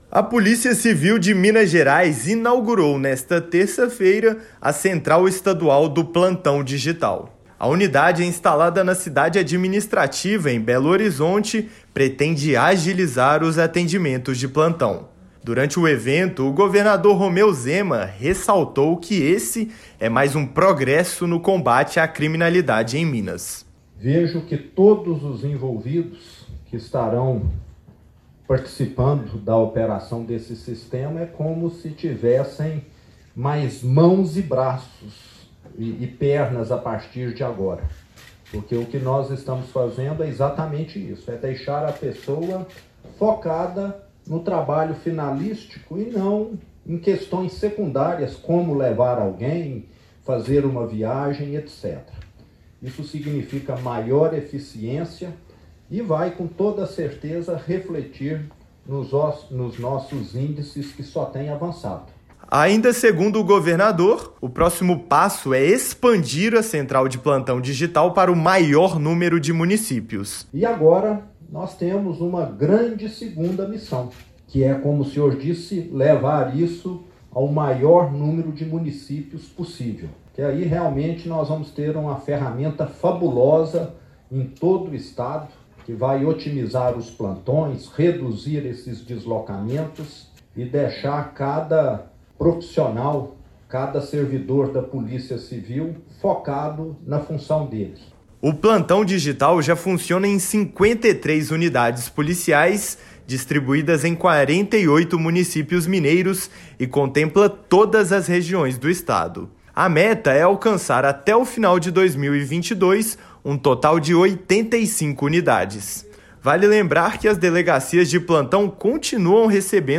A Polícia Civil de Minas Gerias inaugurou, nesta terça-feira (21), a Central Estadual do Plantão Digital, instalada na Cidade Administrativa. A unidade vai agilizar os atendimentos. Ouça a matéria de rádio.